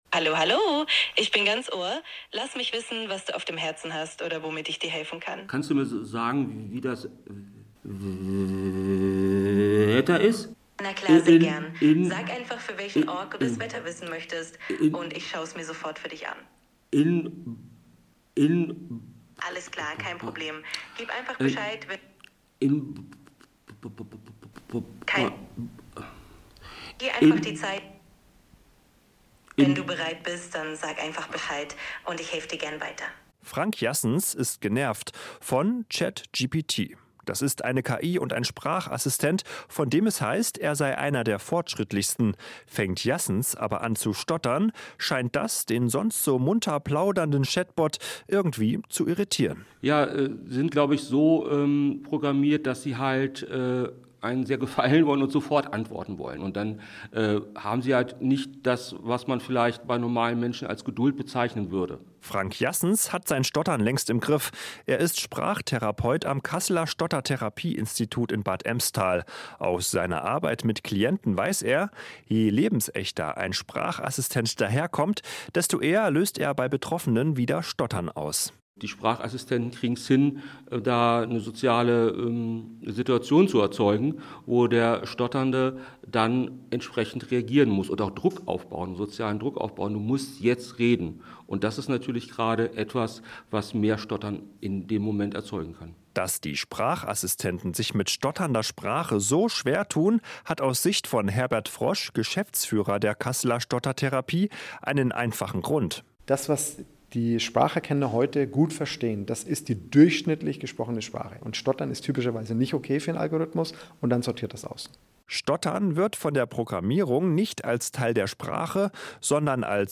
Radiobeitrag des Hessischen Rundfunks hören (hr-info, 3:22 min), der anlässlich des Welttag des Stotterns 2025 produziert wurde.